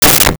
Balloon Pop
Balloon Pop.wav